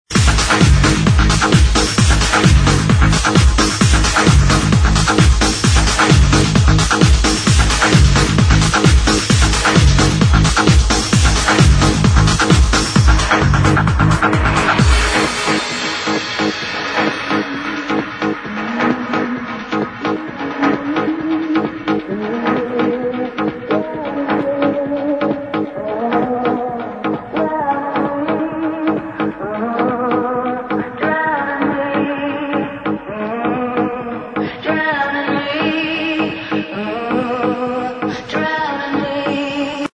the girl is singing